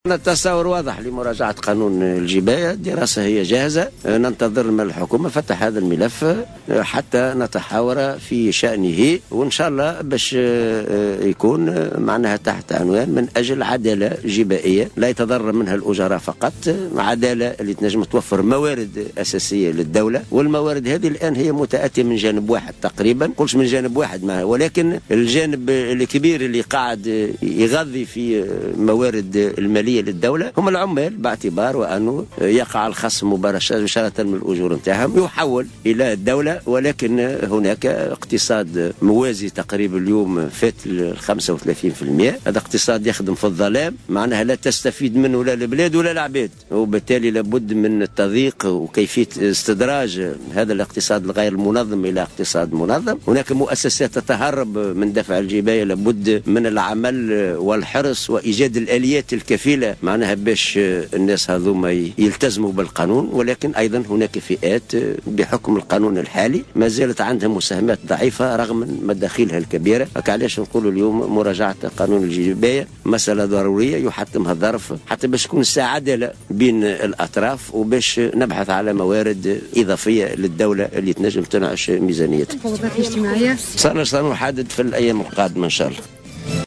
دعا الأمين العام لاتحاد الشغل،حسين العباسي اليوم على هامش افتتاح مؤتمر الجامعة العامة للمالية إلى ضرورة مراجعة قانون الجباية و وضع الآليات الكفيلة للحد من ظاهرة الاقتصاد الموازي